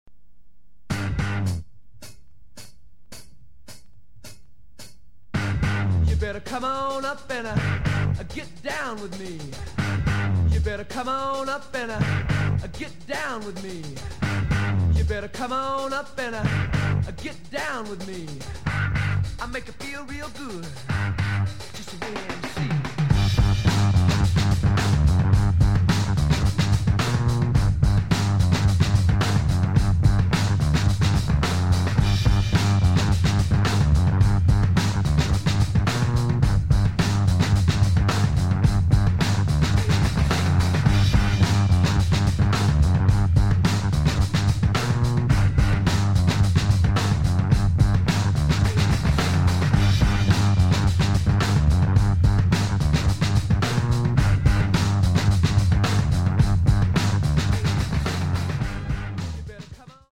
a storming piece of dancefloor action
breakbeat tinged, funky as hell, dancefloor music.